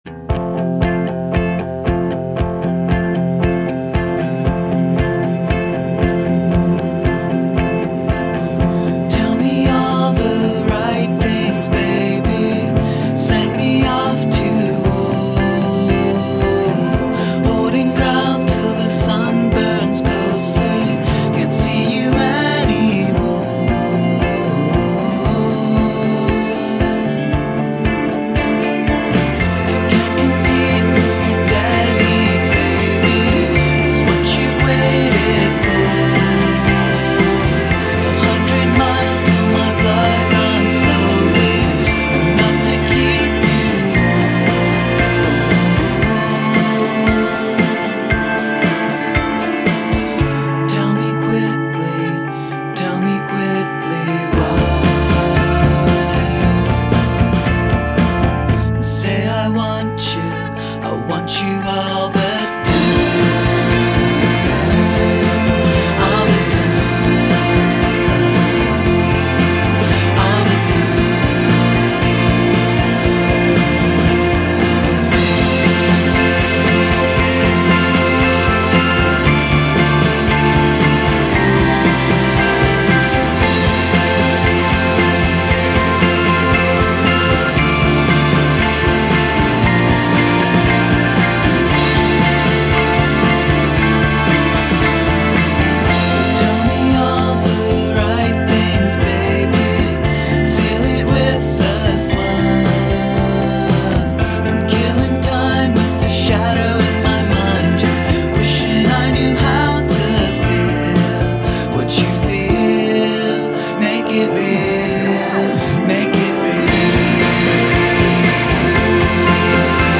Light, airy, slightly depressing - all the good stuff.